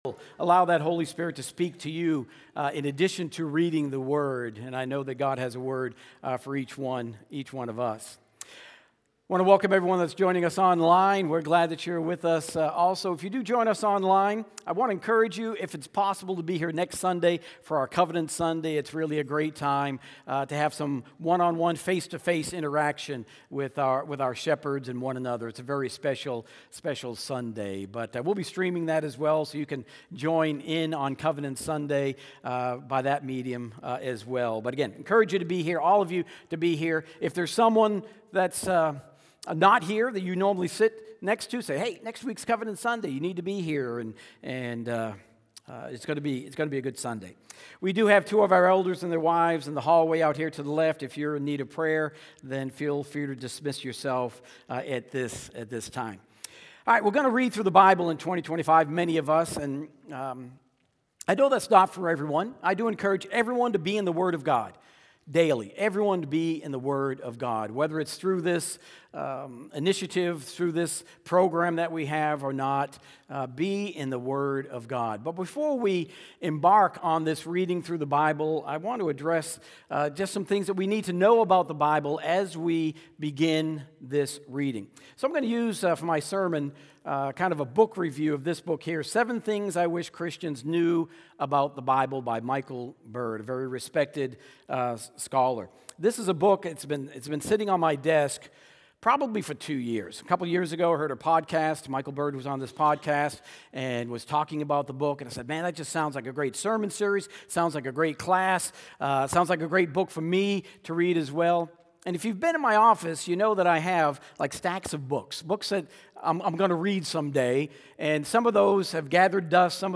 Sermons | Park Avenue Church of Christ